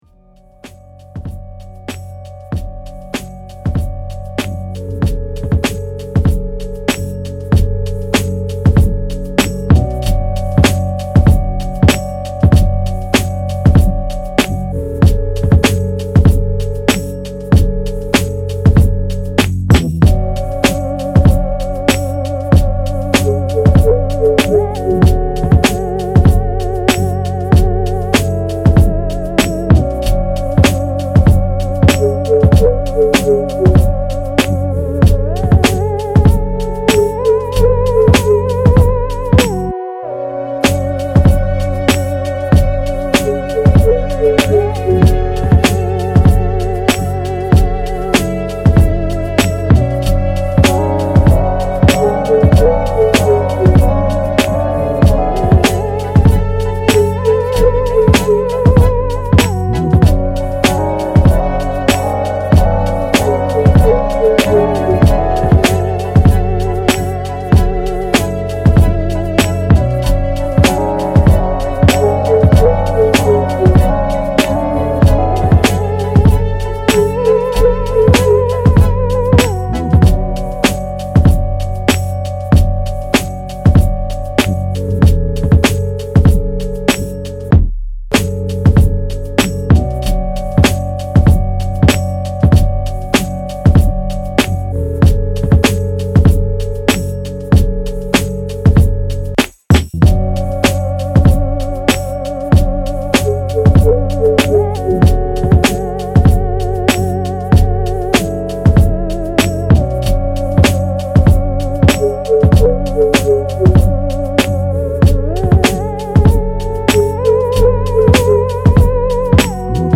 Underground Hip Hop
instrumental